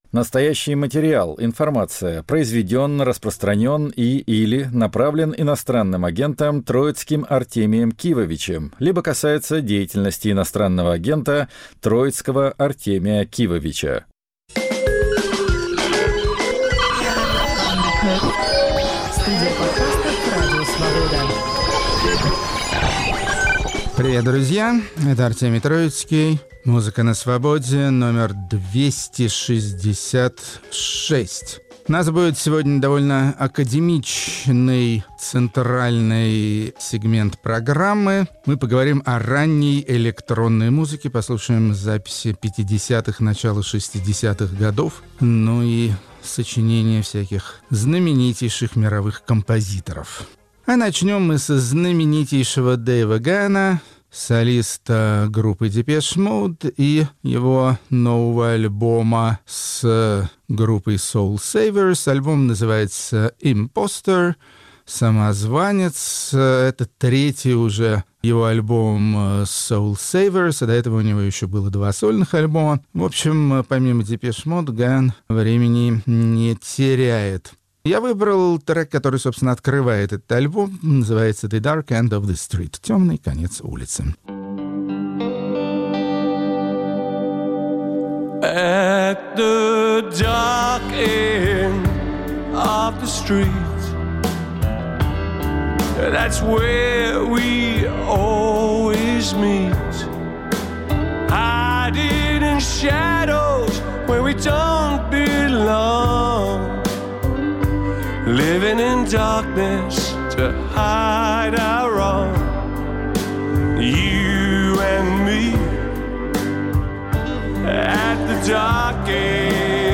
266-й выпуск подкаста и радиопрограммы "Музыка на Свободе" посвящён странным звукам механического и технического типа, которые составили целое экспериментальное творческое направление.